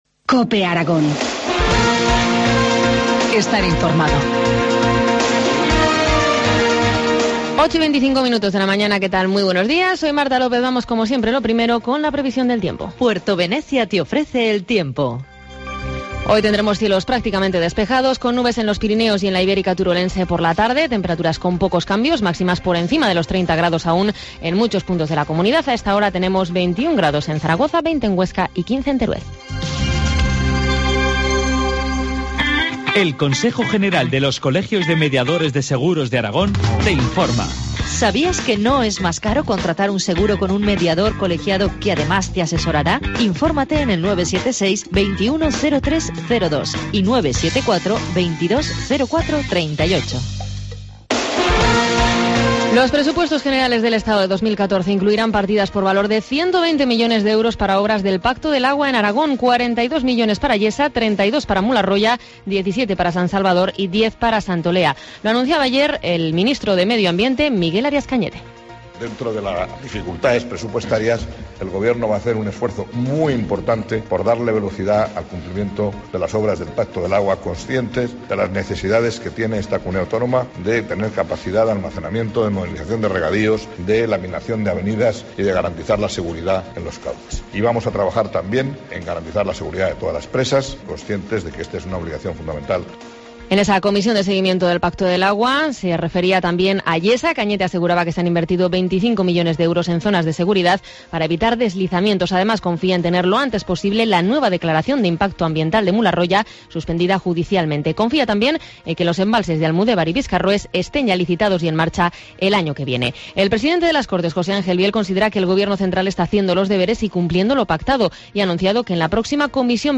Informativo matinal, miércoles 4 de septiembre, 8.25 horas